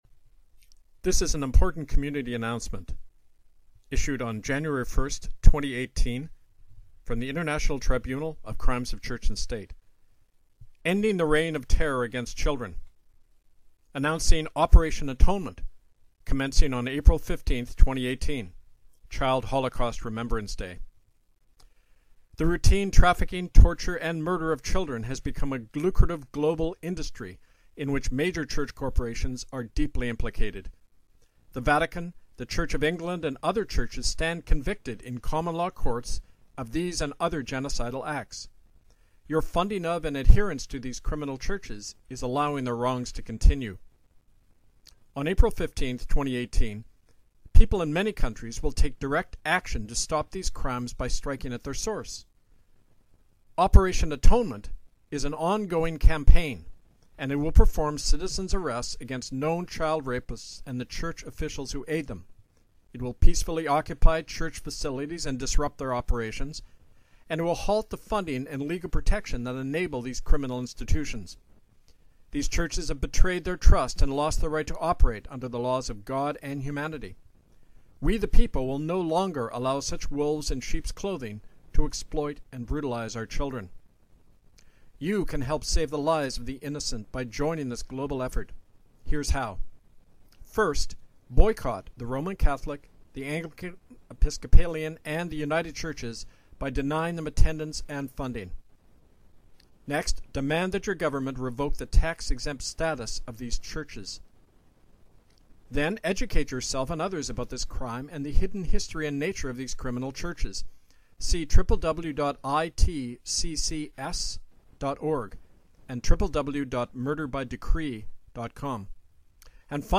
Replay of April 30,2017 Radio Free Kanata production